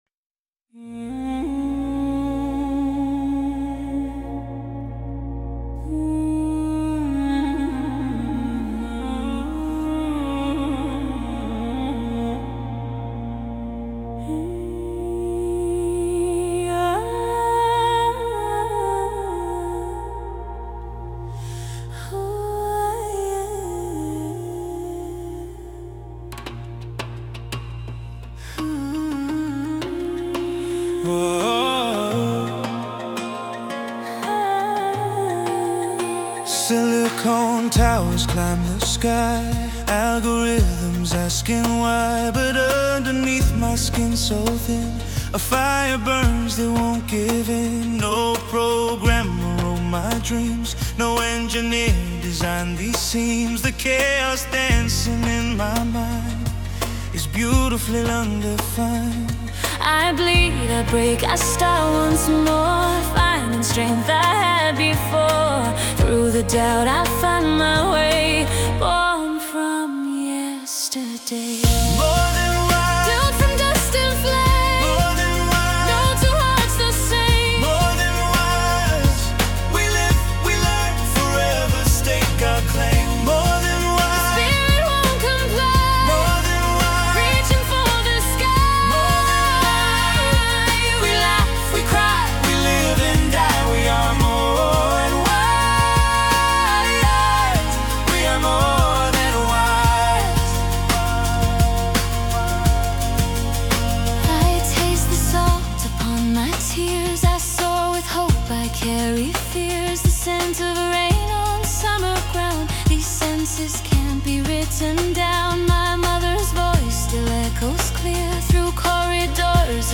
Musical poetry
Most vocals are AI generated.